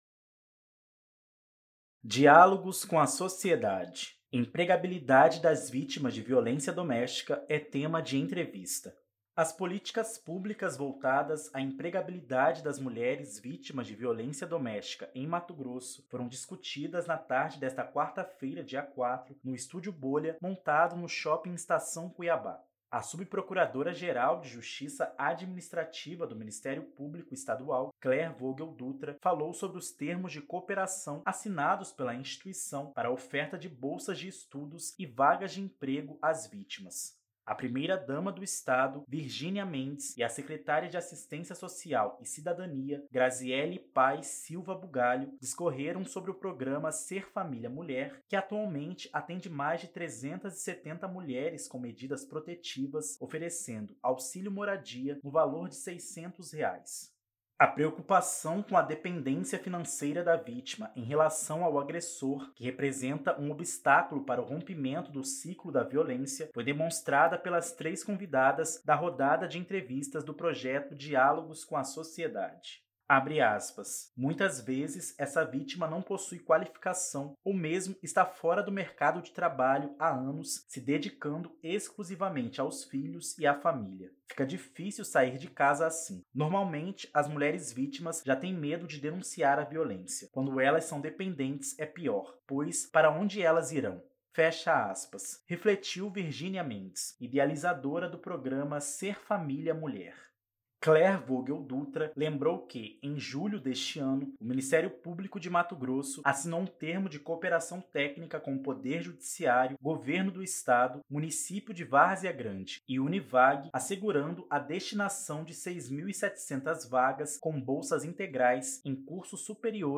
Empregabilidade das vítimas de violência doméstica é tema de entrevista
As políticas públicas voltadas à empregabilidade das mulheres vítimas de violência doméstica em Mato Grosso foram discutidas na tarde de quarta-feira (4) no estúdio “bolha” montado no Shopping Estação Cuiabá.
Empregabilidade das vítimas de violência doméstica é tema de entrevista.mp3